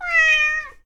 sounds_cat_meow.ogg